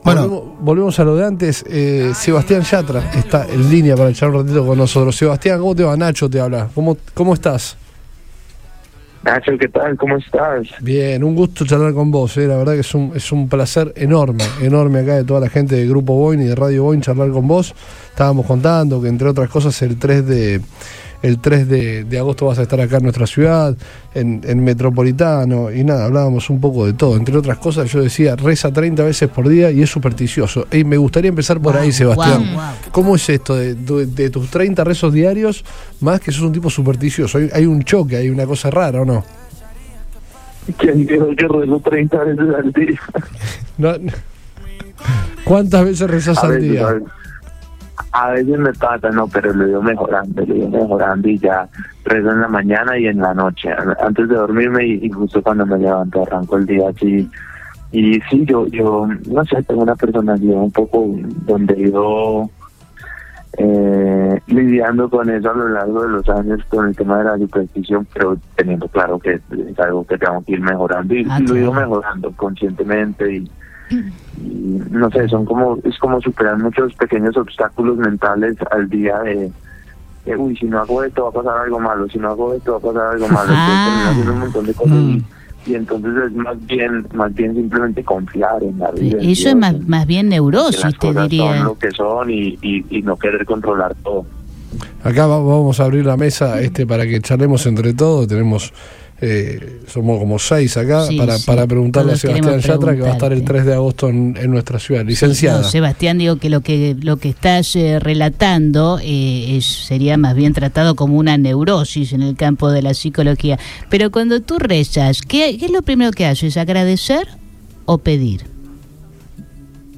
Uno de los cantantes del momento, Sebastián Yatra, dialogó con Todo Pasa para Radio Boing, donde presentó lo que será su show en la ciudad en el próximo mes de agosto. Además, el colombiano contó sus experiencias de cantar en la ceremonia de entrega de los Premios Óscars, sus metas dentro de la profesión y sus inicios musicales.